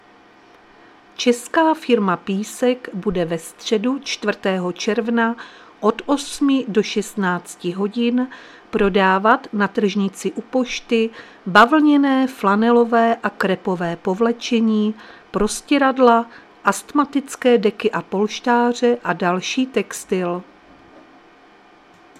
Záznam hlášení místního rozhlasu 2.6.2025